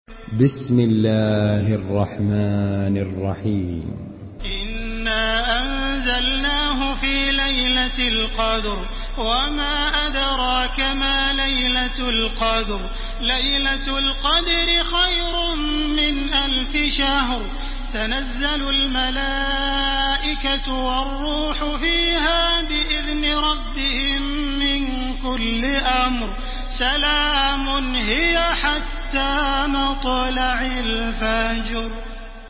Listen and download the full recitation in MP3 format via direct and fast links in multiple qualities to your mobile phone.
Makkah Taraweeh 1430
Murattal